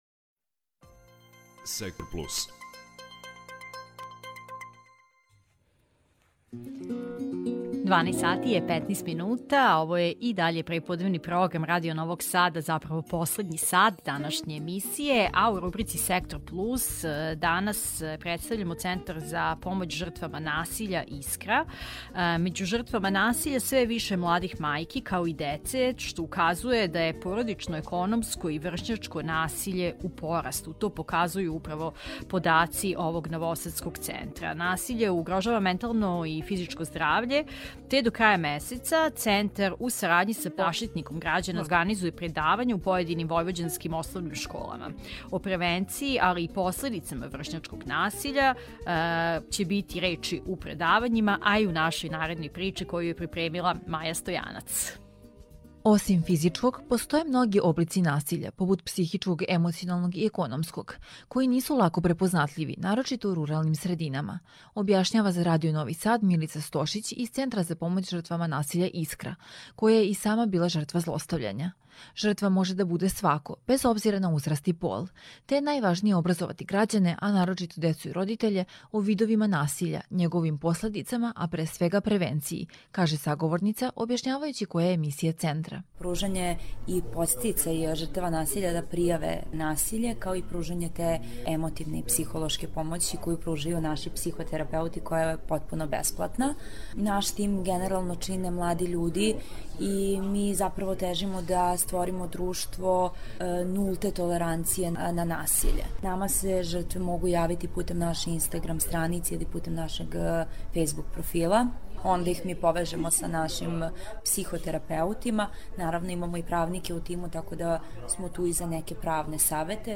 Audio zapis sa gostovanja na Radiju Novi Sad